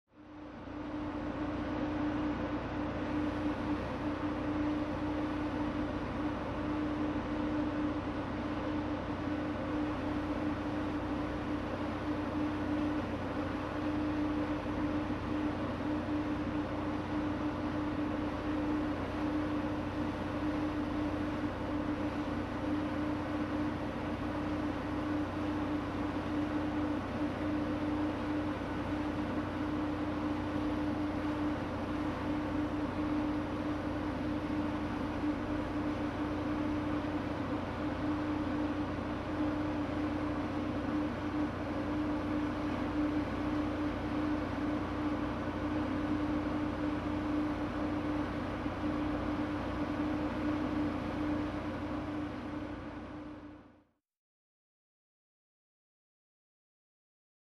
Idle, Bus | Sneak On The Lot